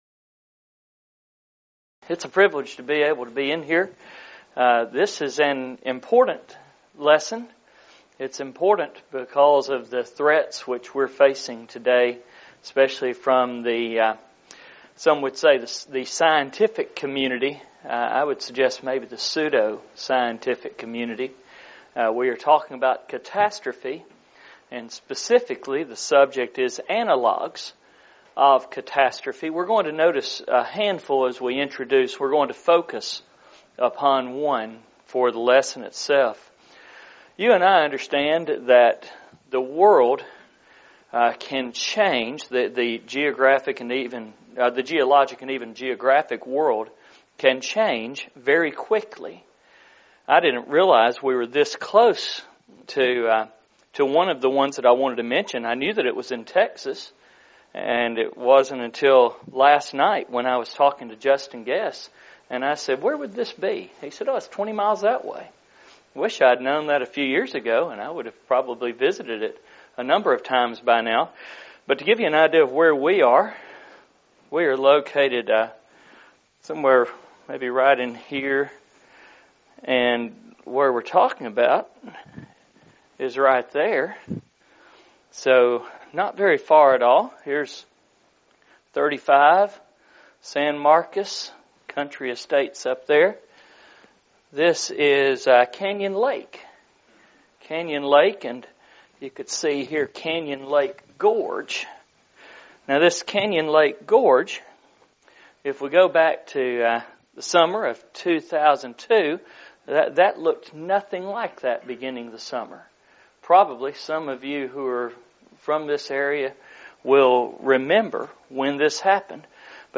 Event: 2019 Focal Point
Preacher's Workshop
lecture